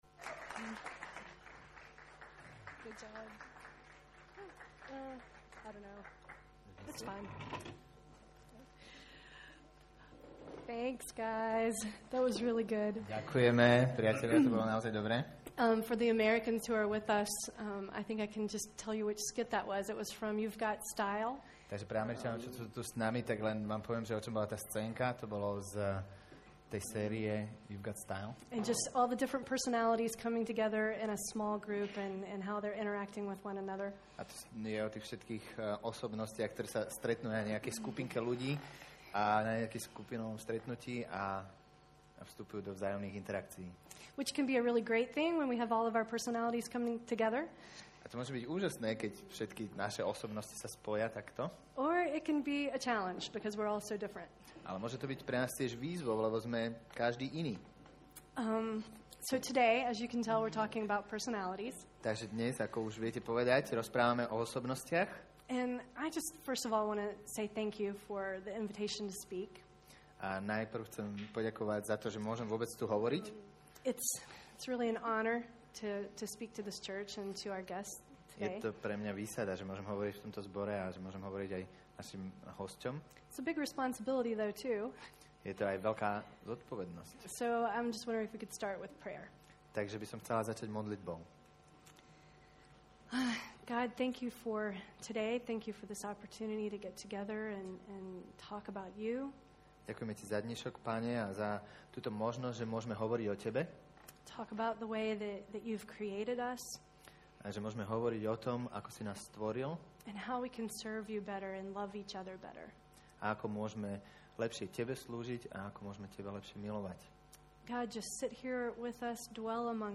Nahrávka kázne Kresťanského centra Nový začiatok z 8. októbra 2007